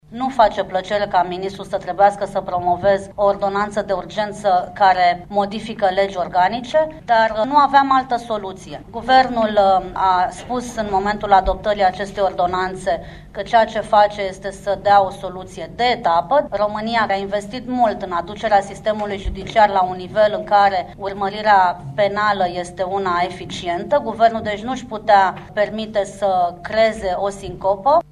Ministrul Justiţiei, Raluca Prună, prezentă la lucrările comisiei, a spus că modificările aduse actului normativ sunt în acord cu deciziile Curţii Constituţionale.